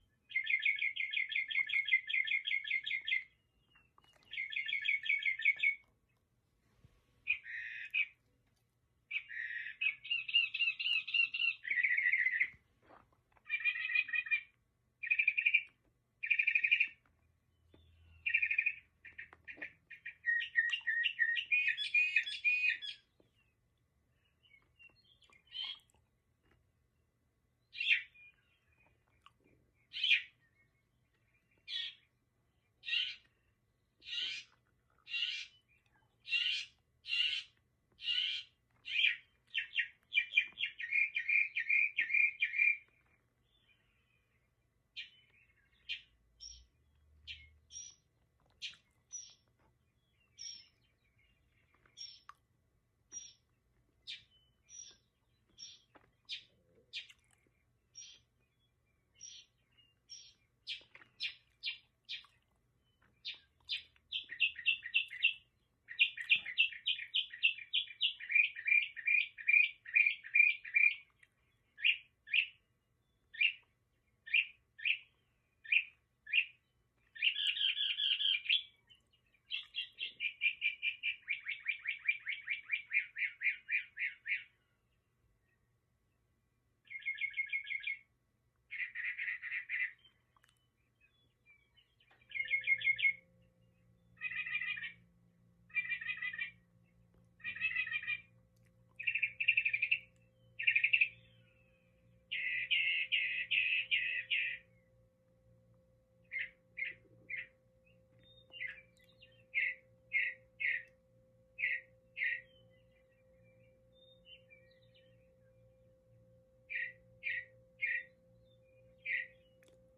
Bird-Song.mp3